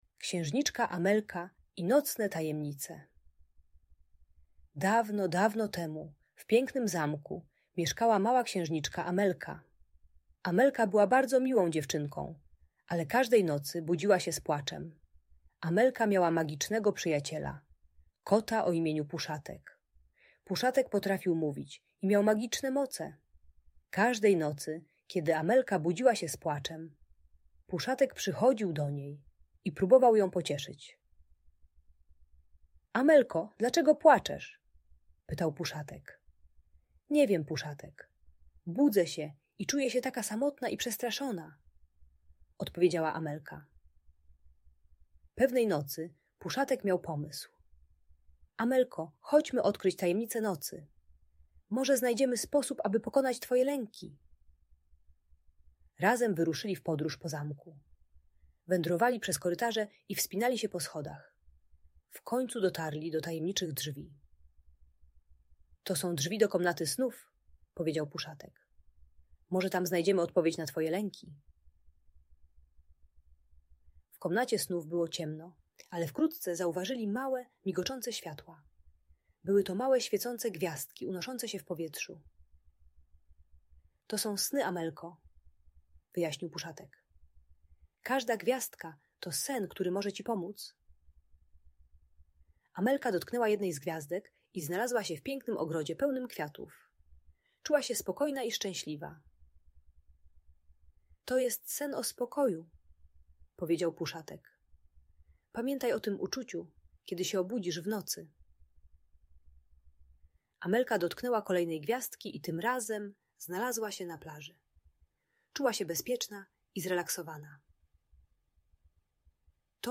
Księżniczka Amelka i Nocne Tajemnice - magiczna historia - Audiobajka dla dzieci